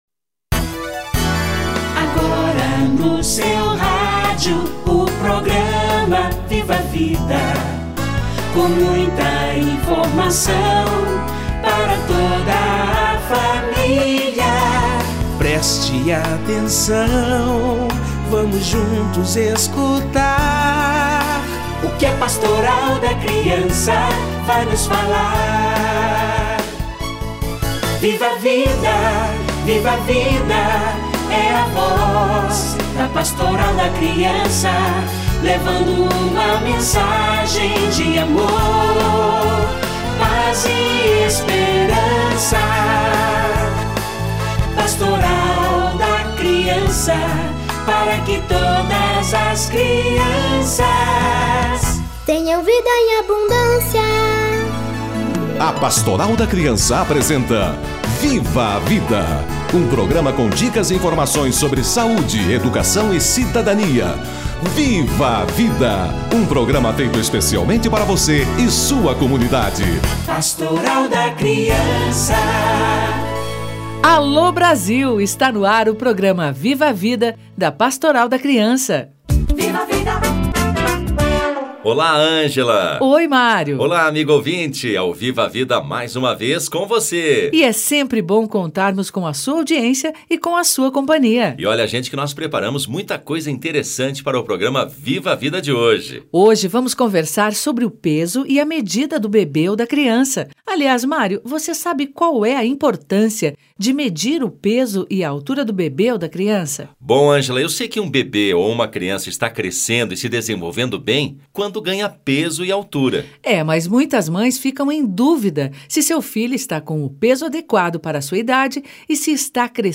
Peso e medida da criança - Entrevista